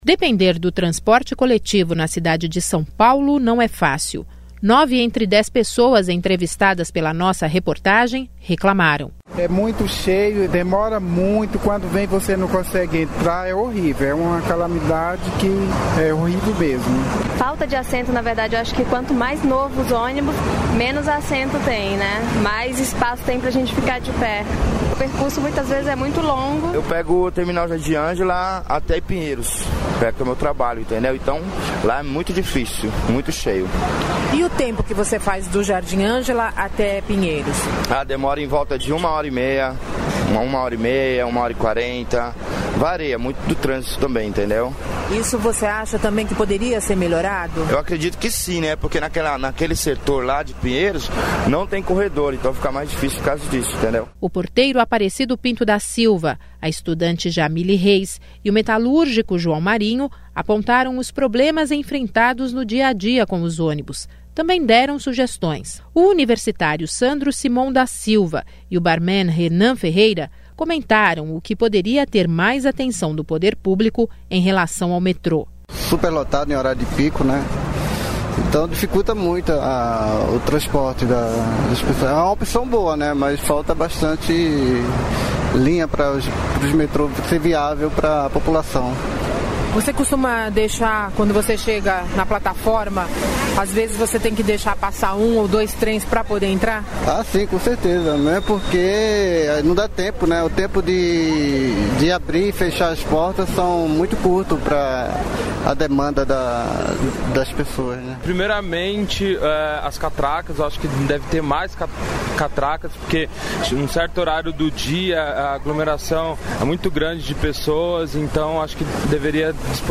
Algumas delas apareceram nesta enquete promovida pelo CBN SP.